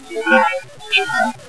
This was a very active cemetery in terms of spirit voices.
This next EVP is a little "whispery" but still pretty clear.